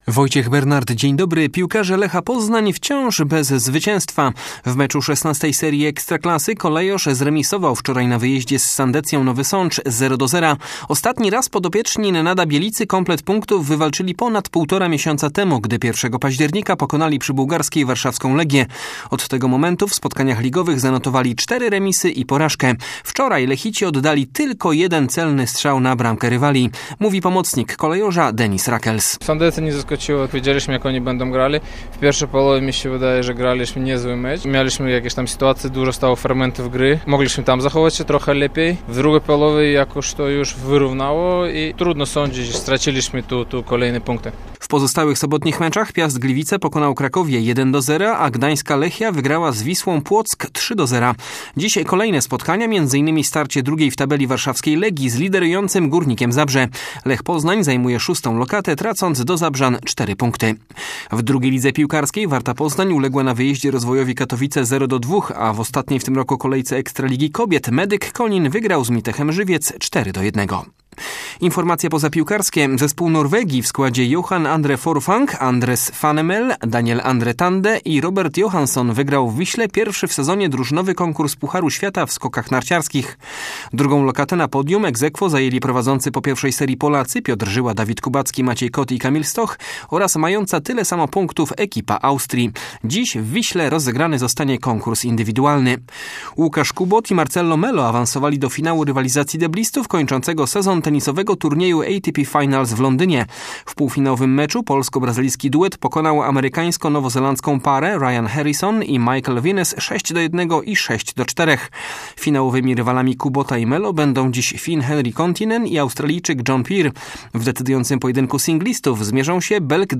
19.11 serwis sportowy godz. 9:05